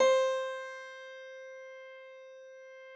53l-pno14-C3.wav